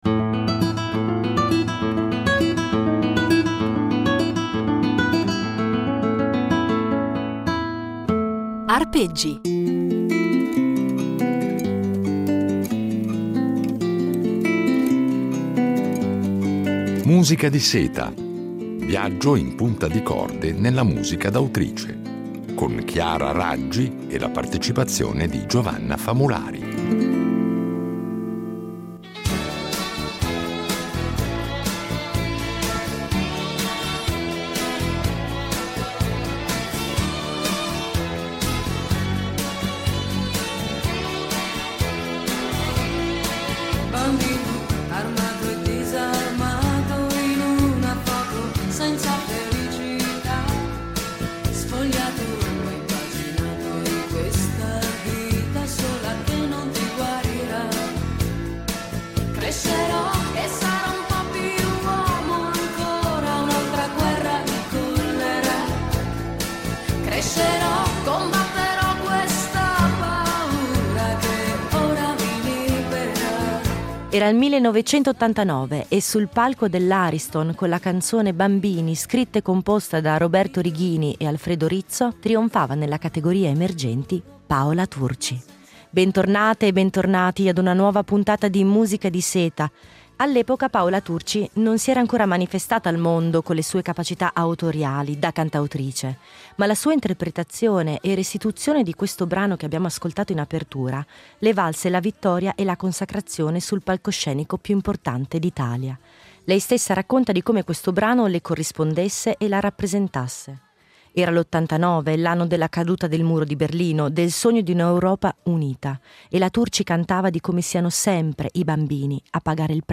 Una serie di Arpeggi impreziosita dalle riletture originali di un duo
violoncellista